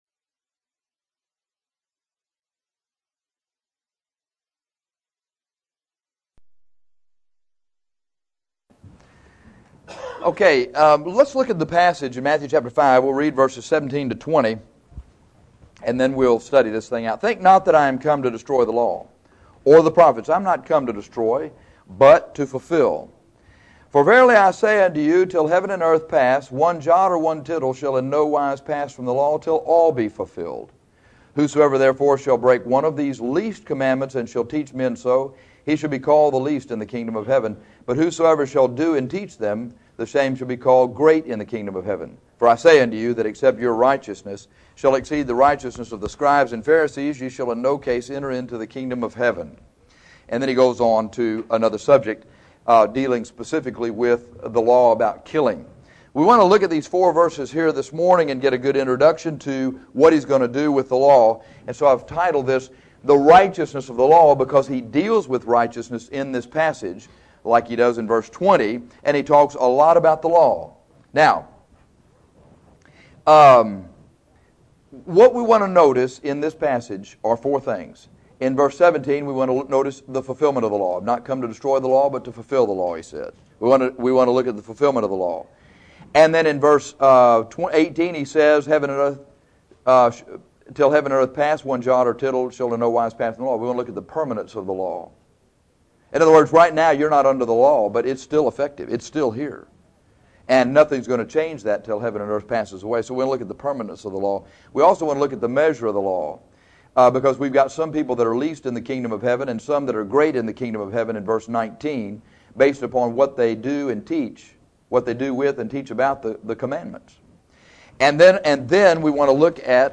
In this passage, Jesus deals with the fulfillment, permanence, measure and righteousness of the law. Great Sunday school lesson.